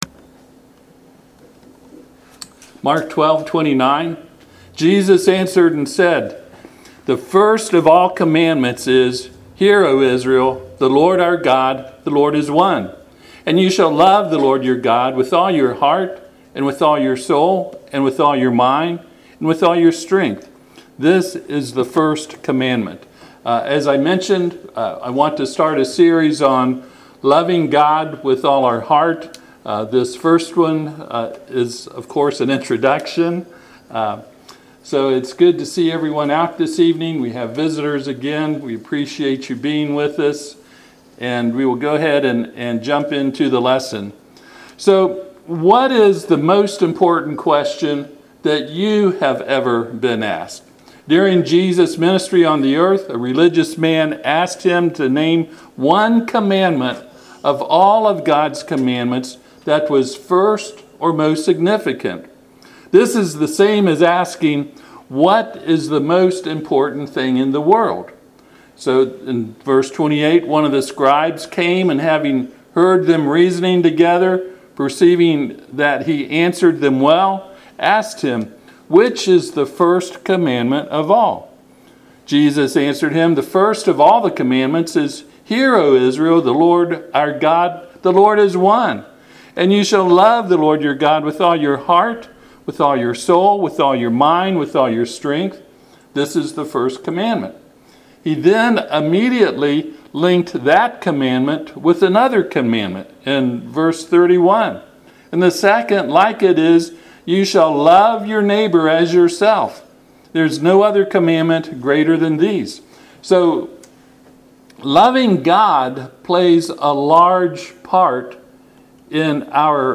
Service Type: Sunday PM